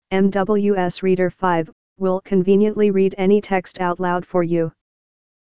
Sprecher für das Vorleseprogramm MWS Reader
Englischsprachige Stimmen (Amerikanisches Englisch)
Microsoft Speech Platform – Runtime Language (Version 11)